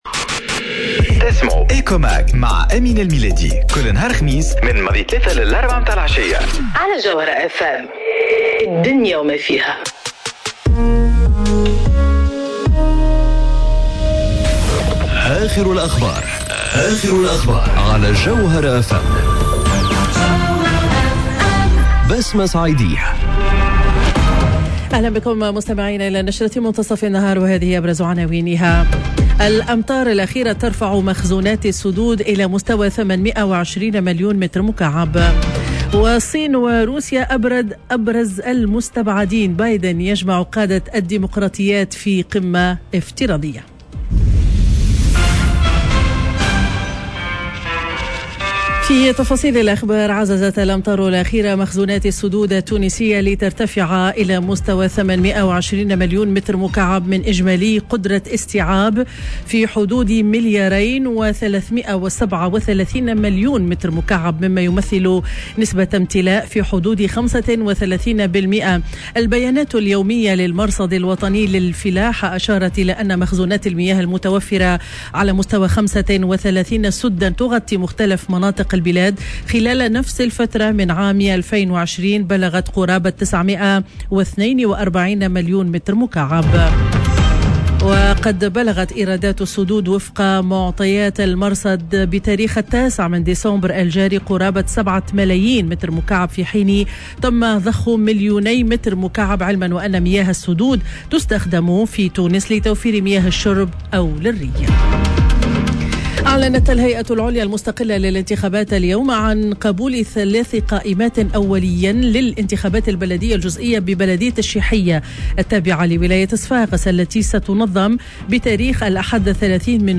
نشرة أخبار منتصف النهار ليوم الخميس 09 ديسمبر 2021